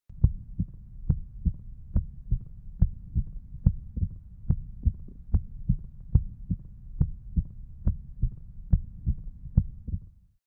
When you put the stethoscope on the apex you heard this sound, what is this sound? mitral sound Deselect Answer mitral stenosis mitral valve replacement click mitral regurgitation None
mitral sound.mp3